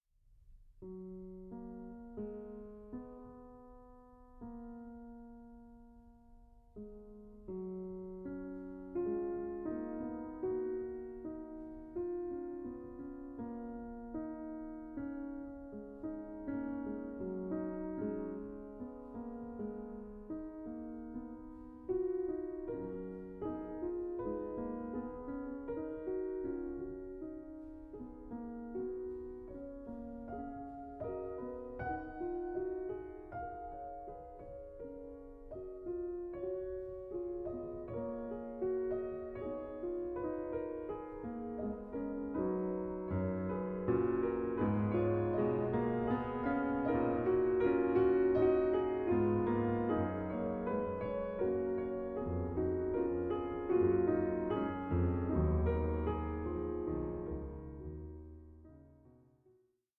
in F-Sharp Major: Prelude 2:12